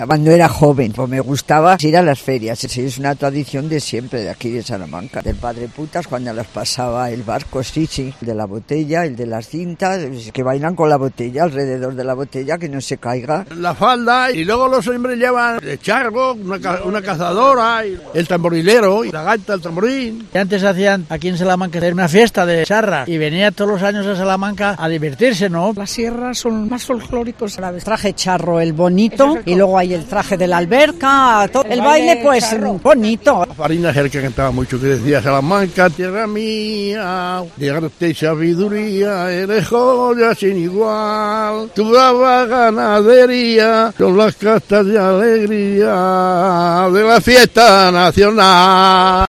Los salmantinos recuerdan el folklore charro e incluso se animan a cantar